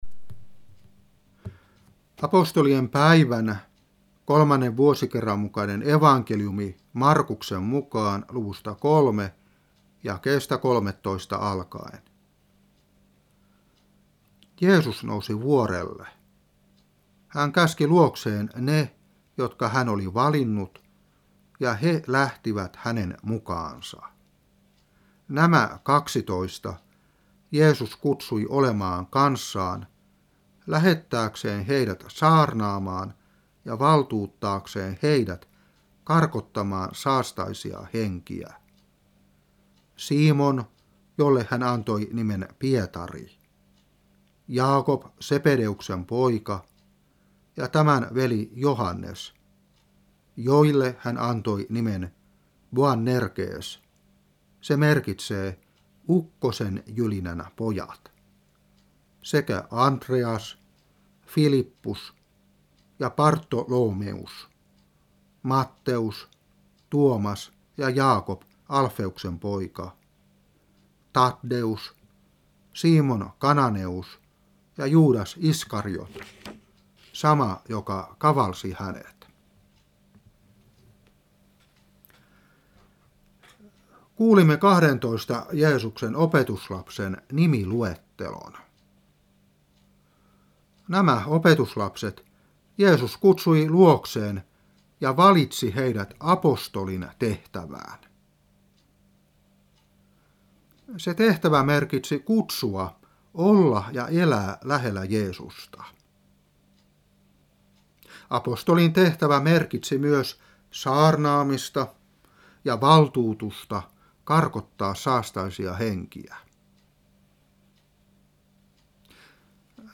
Saarna 2006-7.